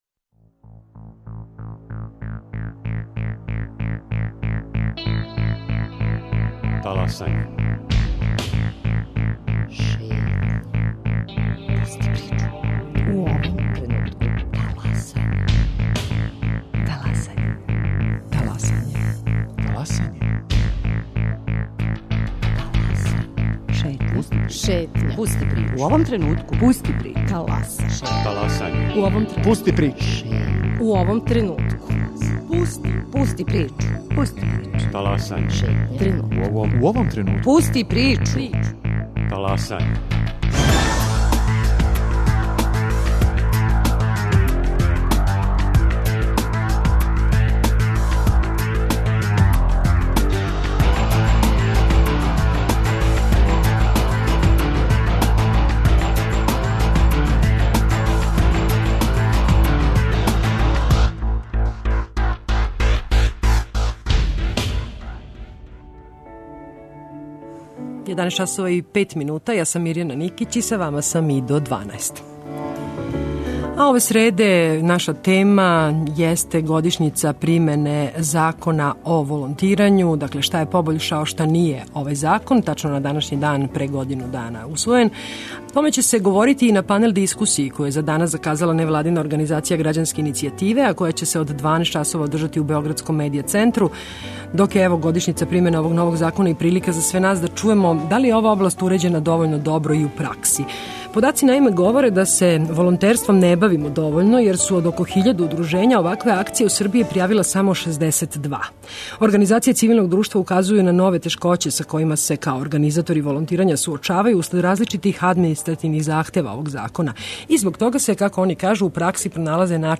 Зашто је и за кога важно развијати волонтерство, и шта би донело прихватање препорука за побољшање и измену Закона, говоре гости из Грађанских иницијатива, Младих истраживача и Бечејског удружења младих.